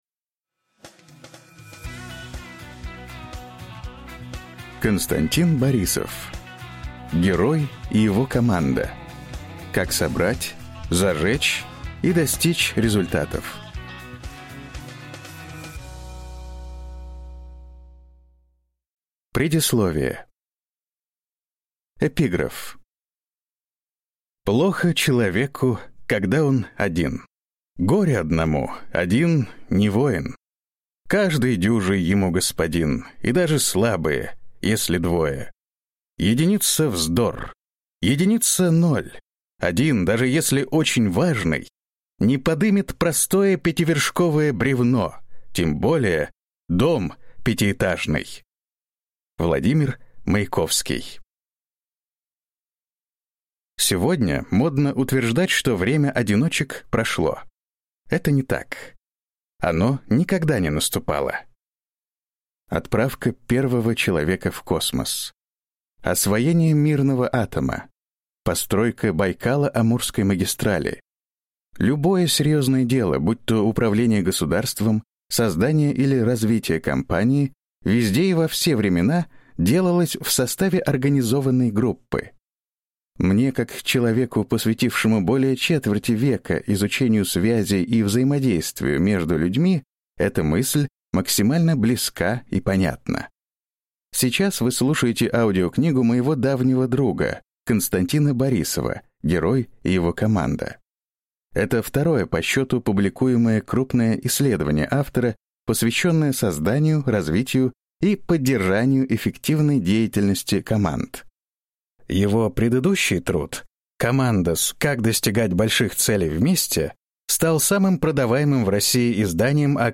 Аудиокнига Герой и его команда. Как собрать, зажечь и достичь результатов | Библиотека аудиокниг